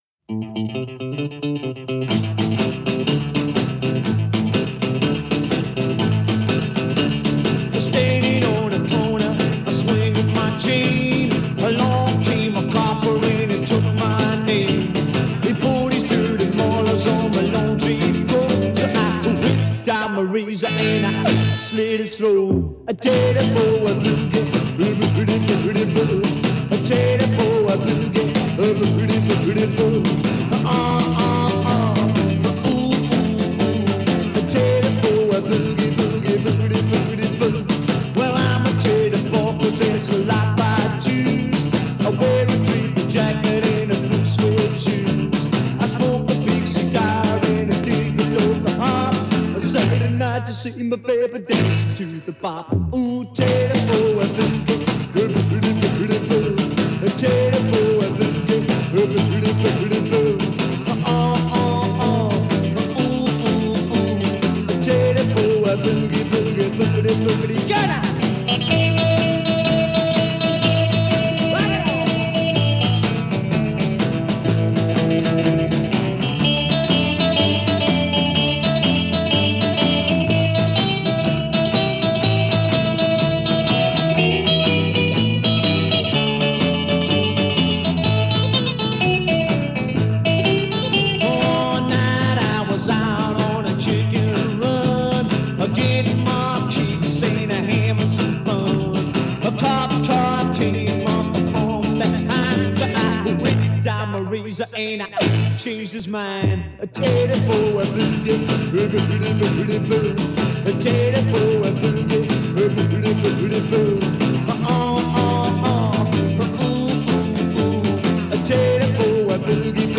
Welsh Rockabilly